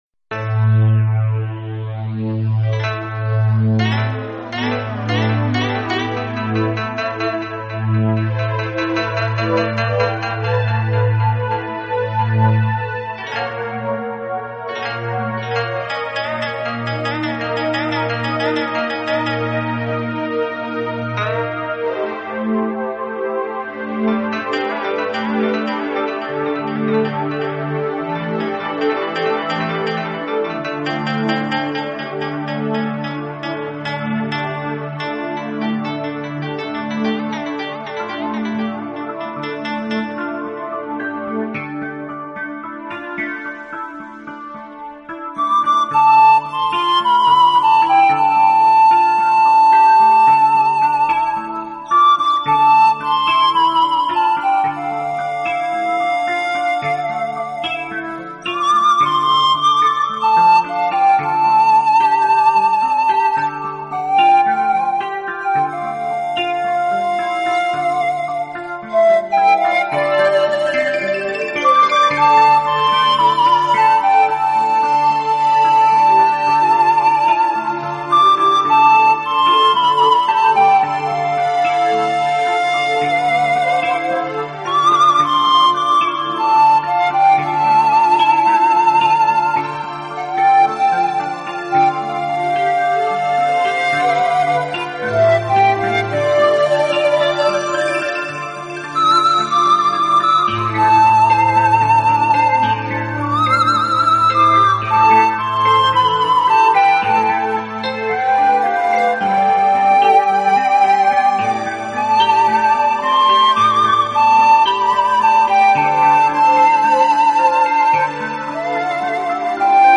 【顶级轻音乐】
而一流的錄音也使之成為試音的經典極品……